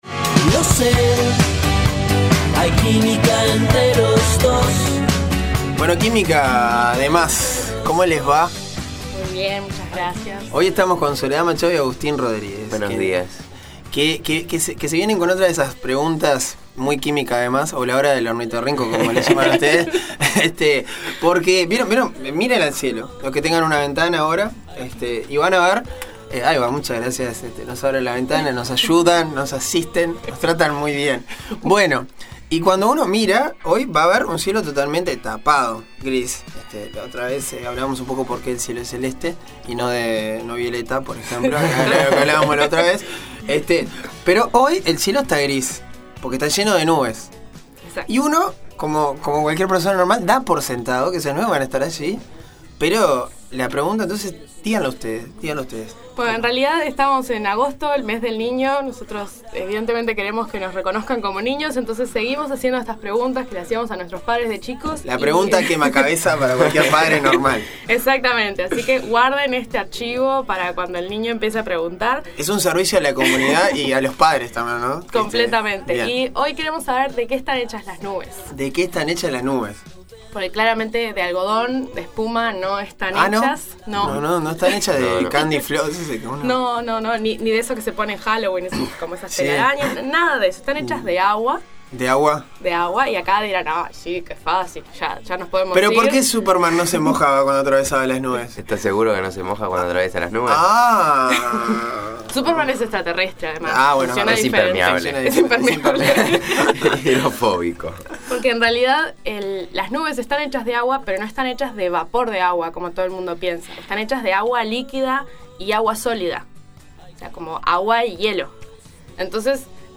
Esta semana en el espacio Química D+ de La Mañana de Uni Radio nos seguimos haciendo preguntas que solían ser recurrentes cuando éramos niños. En la edición anterior nos preguntamos sobre el color del cielo y en esta oportunidad conocimos cuál es la composición de las nubes y por qué no se caen.